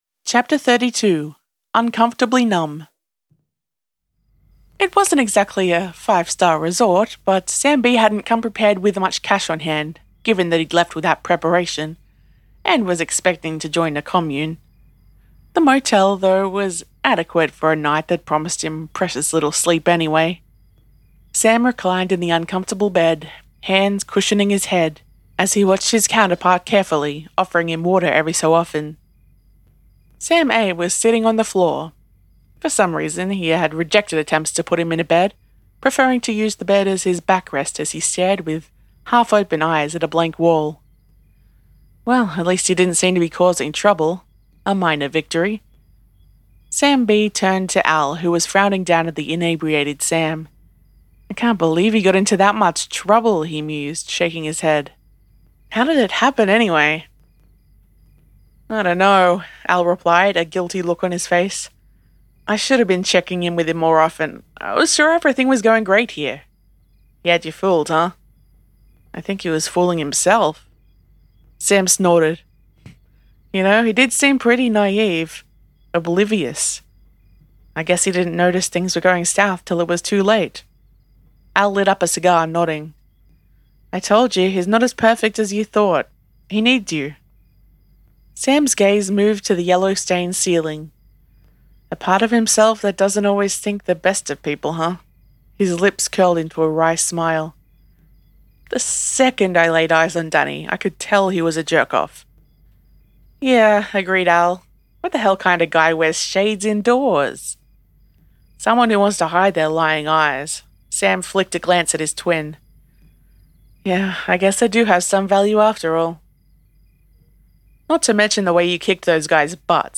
Listen to/download this chapter narrated by the author: